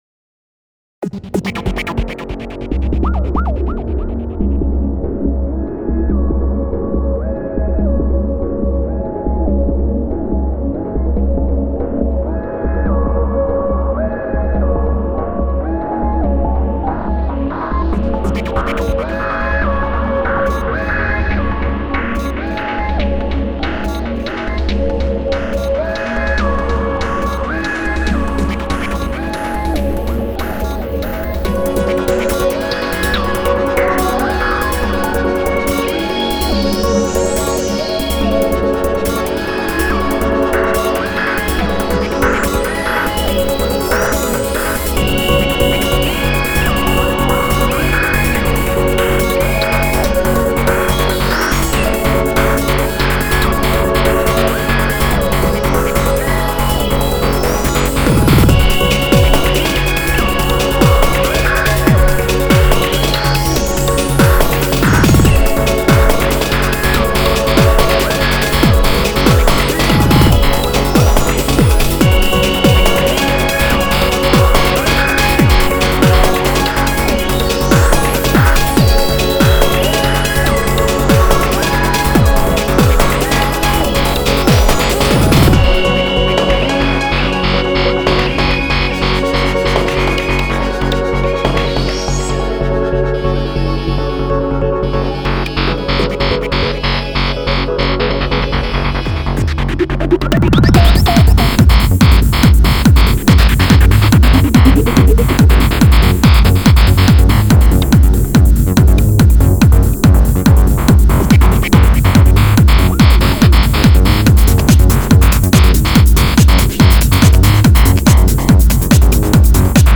lintro est bcp trop longue par rapport au reste.
c'est très bien fait c'est très space .